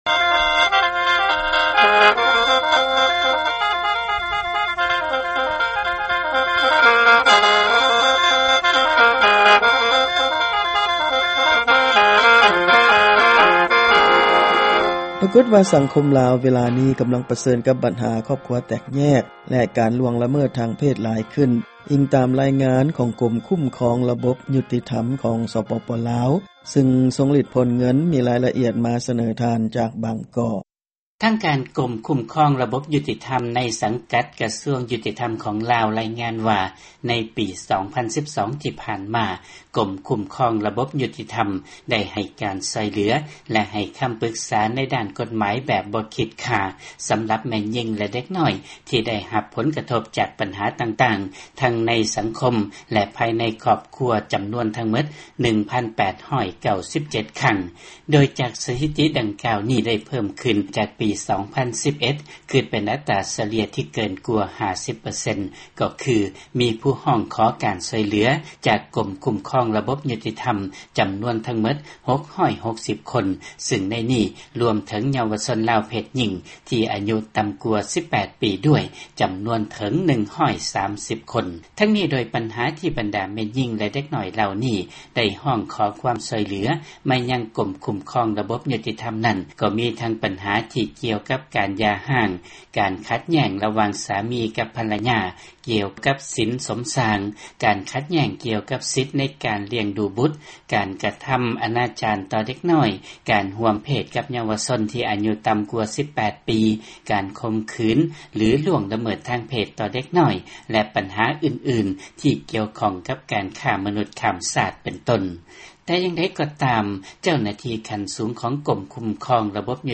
ຟັງລາຍງານບັນຫາສັງຄົມໃນລາວ